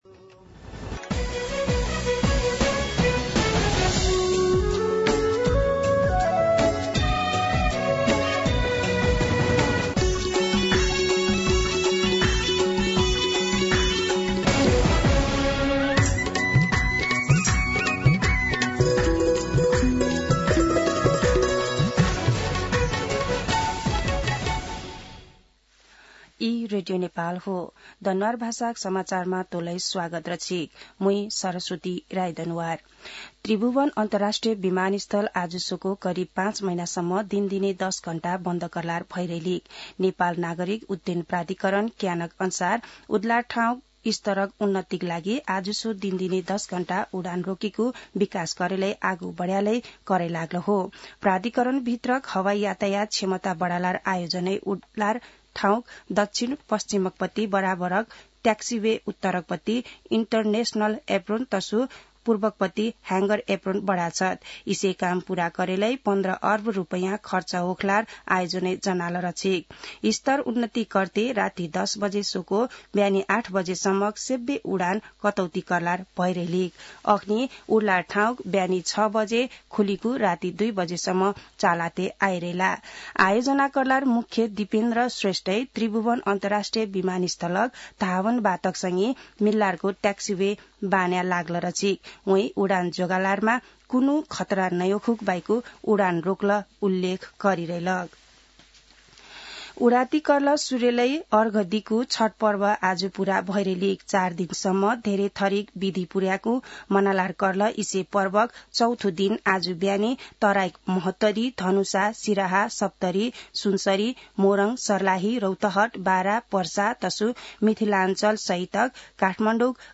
दनुवार भाषामा समाचार : २४ कार्तिक , २०८१
Danuwar-News-23.mp3